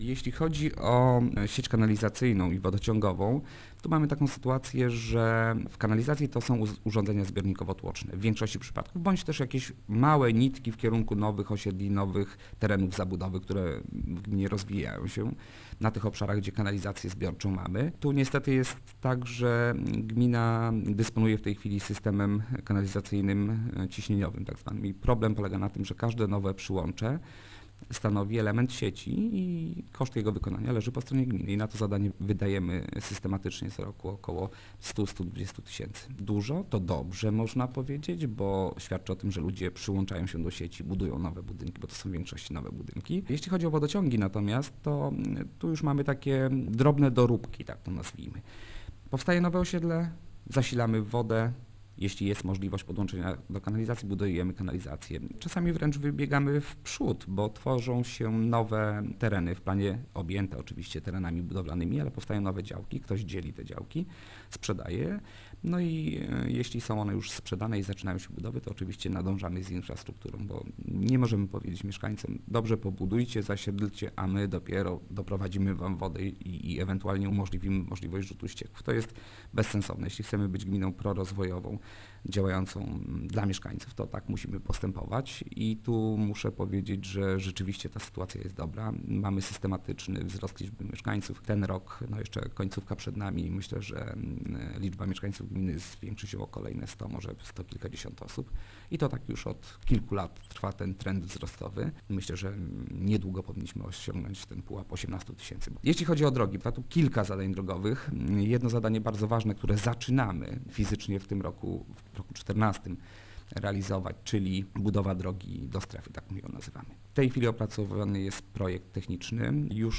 Będą one przeznaczone na realizację zadań, dotyczących infrastruktury społecznej, w tym dokończenie budowy świetlic w Karwaczu i Malcanowie, a także rozbudowę infrastruktury wodno-kanalizacyjnej i drogowej - wylicza wójt Gminy Łuków Mariusz Osiak: